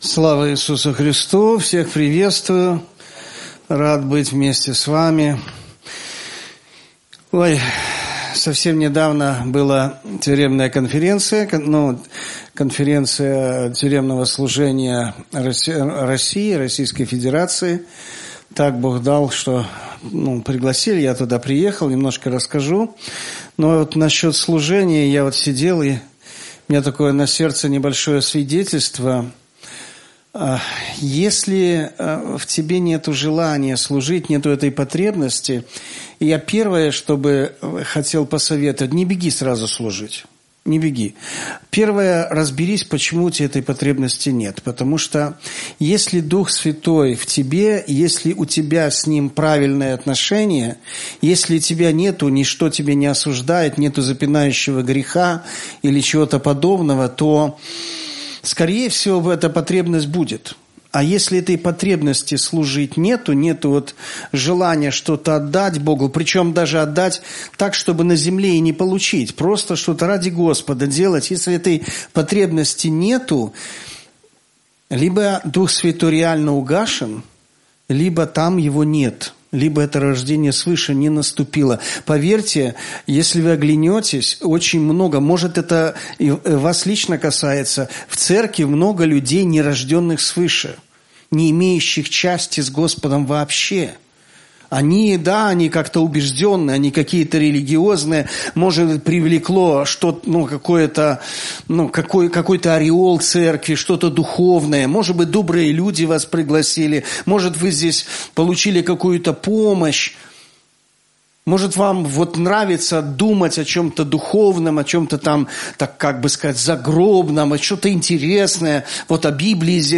Гефсіманія - Пропаведзі